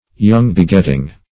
young-begetting \young"-be*get`ting\, adj. (Biology)